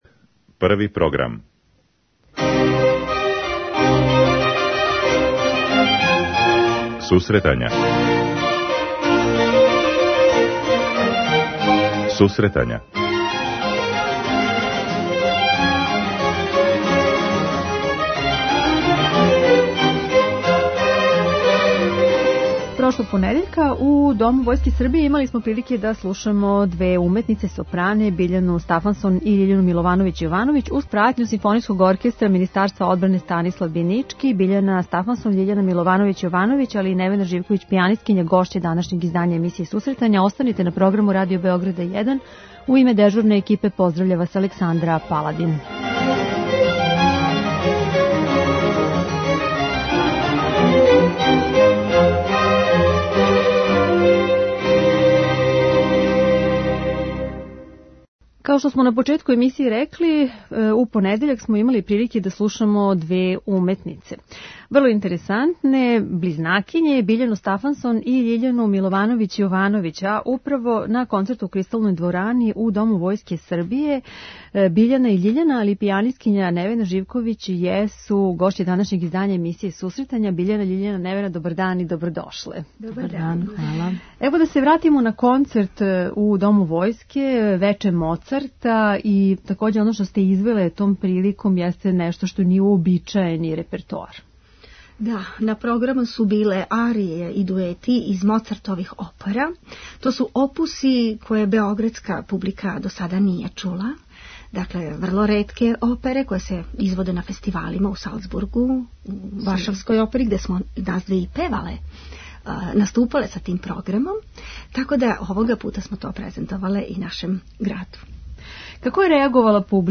преузми : 10.80 MB Сусретања Autor: Музичка редакција Емисија за оне који воле уметничку музику.